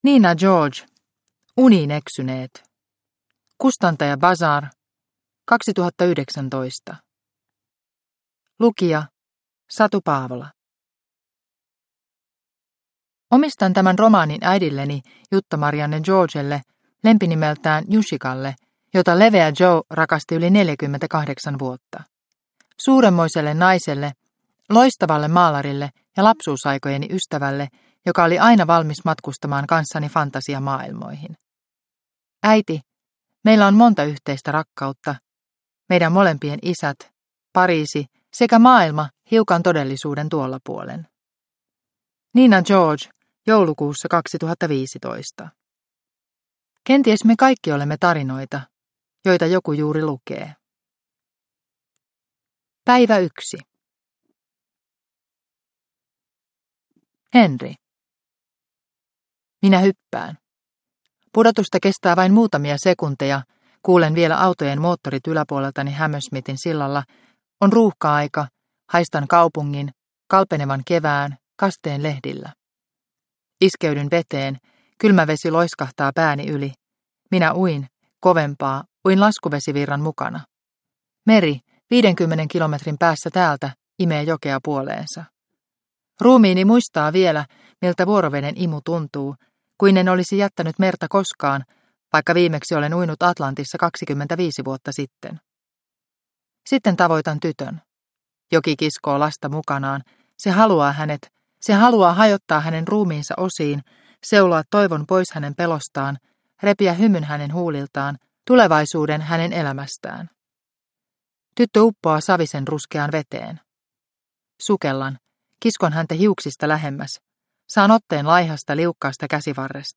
Uniin eksyneet – Ljudbok – Laddas ner